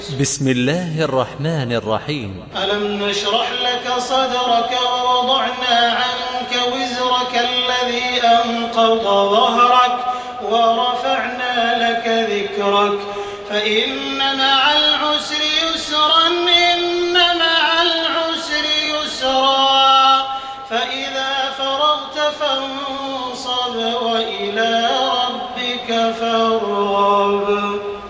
تحميل سورة الشرح بصوت تراويح الحرم المكي 1428
مرتل حفص عن عاصم